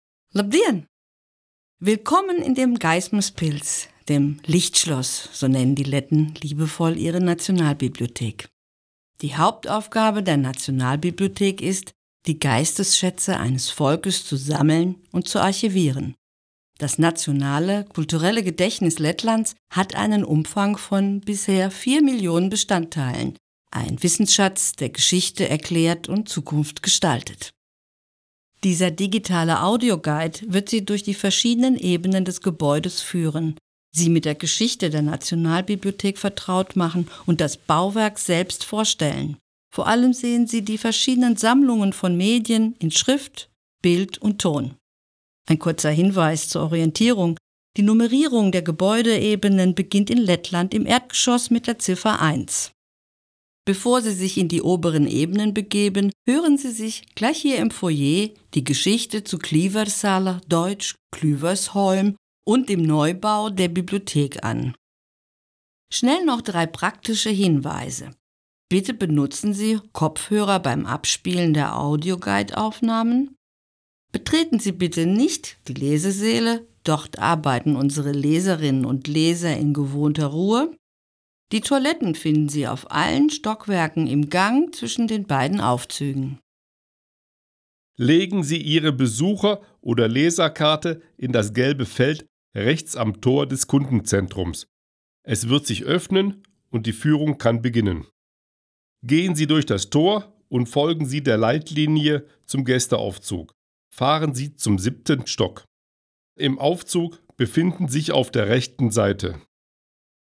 Tūrisma gidi